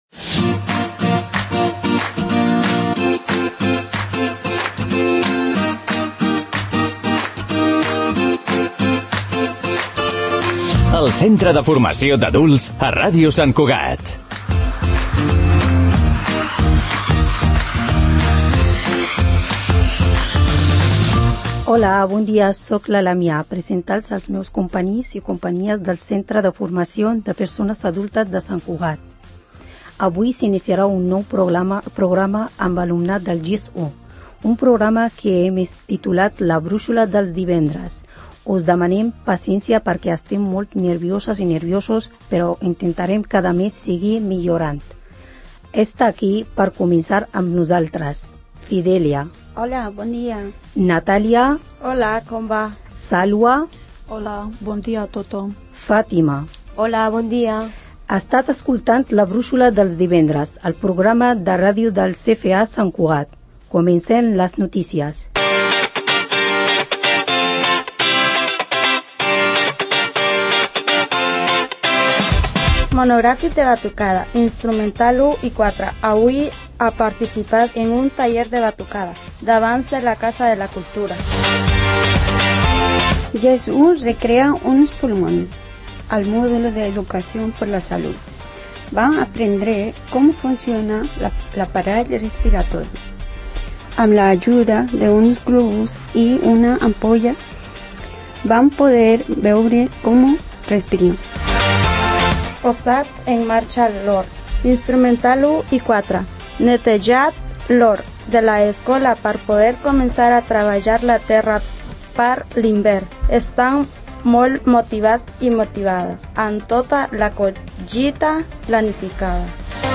L’alumnat Graduat d’Educació Secundària (GES 1) del Centre de Formació de persones Adultes (CFA Sant Cugat) arranca una segona temporada del programa de ràdio amb nou alumnat. En aquest primer pòdcast ens han compartit les notícies del centre com ja va sent habitual, ens han explicat la història ‘La gran fàbrica de les paraules’ escrita per Agnès de Lestrade i Valeria Docampo i han acabat amb una secció musical relacionada amb el fil d’aquesta: l’amor.